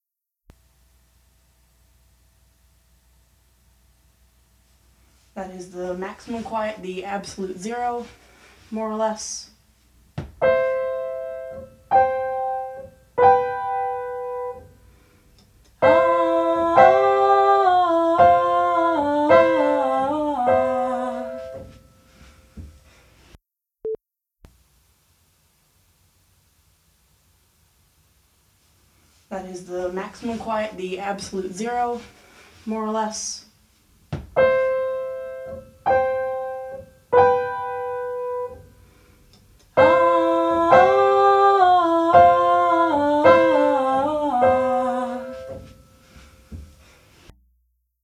Help with buzzing, don't know what else to try
#1 [USA] mains-electricity hum, which consists of multiples of 60Hz , e.g. 60, 120, 180, … , 660, … #2 computer-buzz, which is usually some multiple(s) of 1000Hz , e.g. 8000Hz in your case.